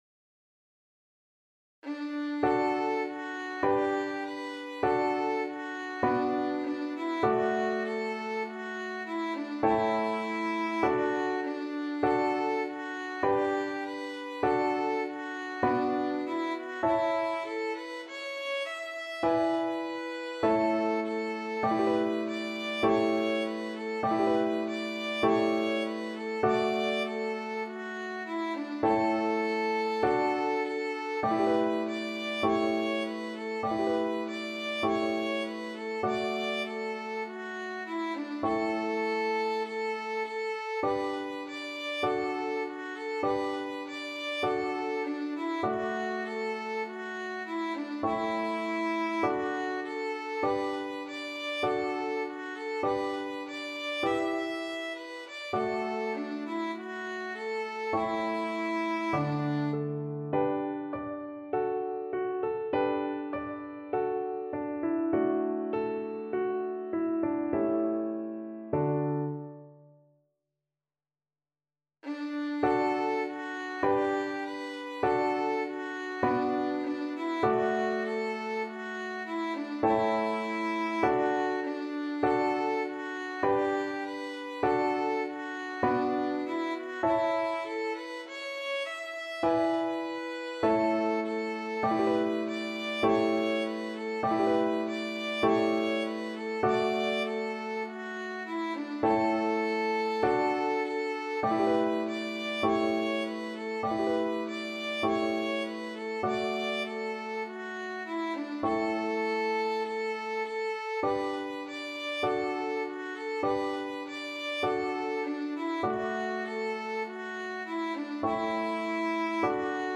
Moderato
4/4 (View more 4/4 Music)
D5-E6
Classical (View more Classical Violin Music)